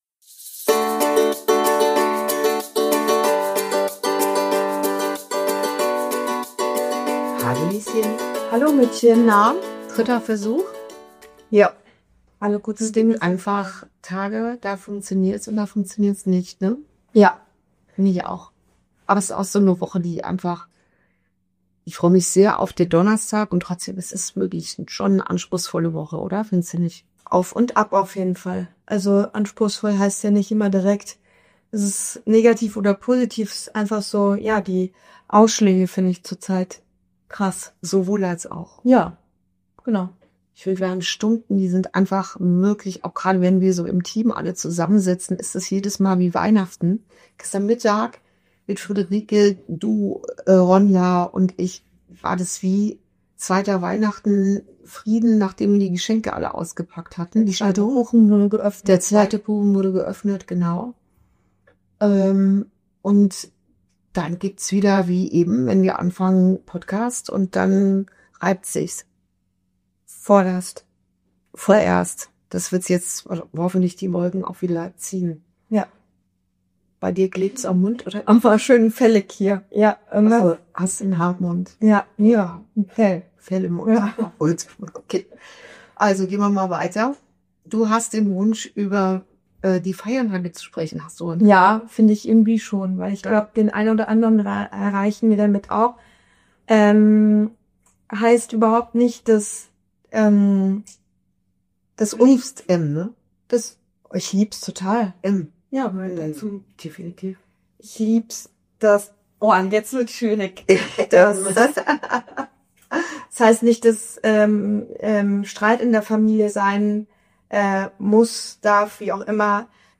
Folge 42: Innerer Frieden in der Vorweihnachtszeit – Warum Stille transformiert ~ Inside Out - Ein Gespräch zwischen Mutter und Tochter Podcast